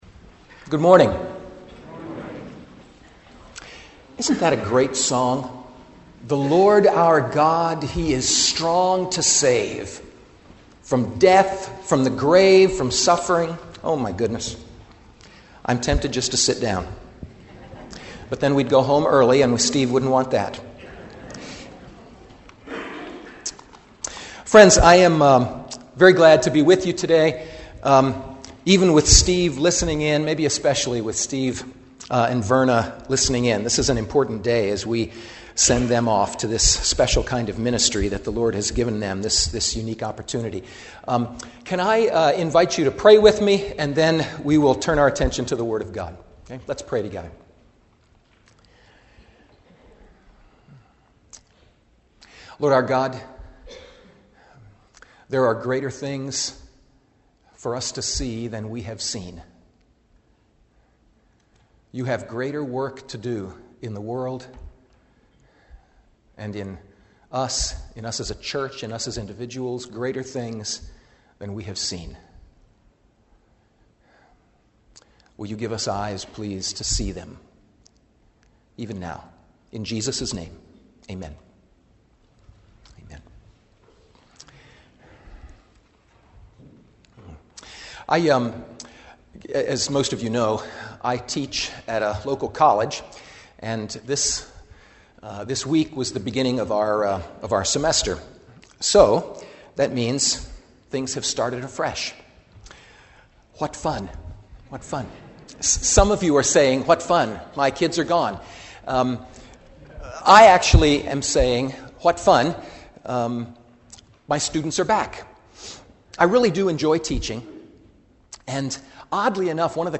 Creation and New Creation — Audio Sermons — Brick Lane Community Church